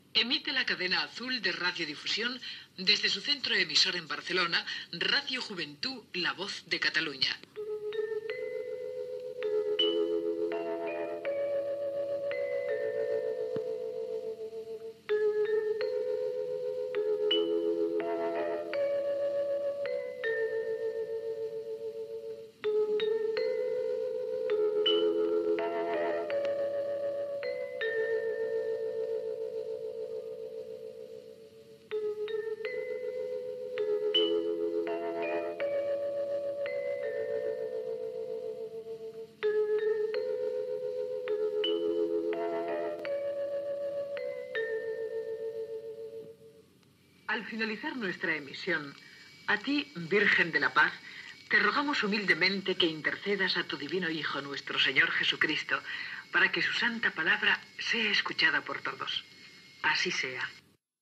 Tancament de l'emissió. Indicatiu de l'emissora i de la cadena, sintonia i prec a la "Virgen de la Paz"